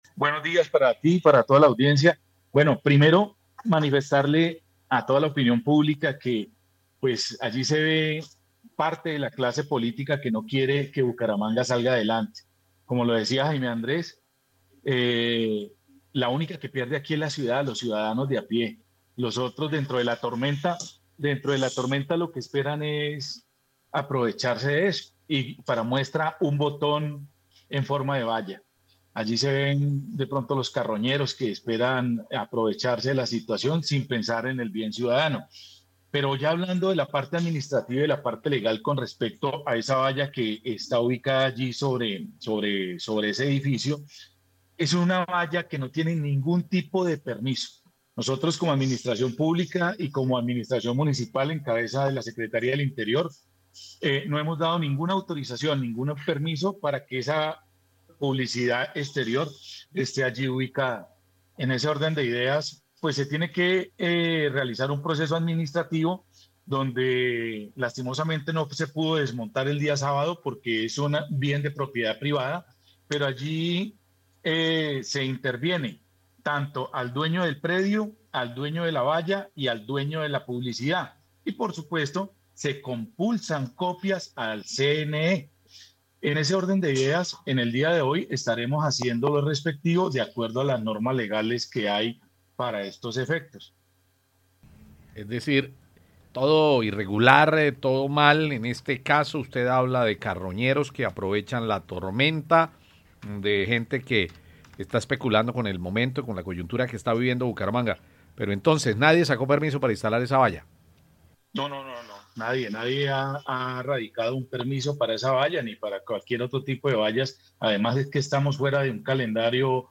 Gildardo Rayo, secretario del interior de Bucaramanga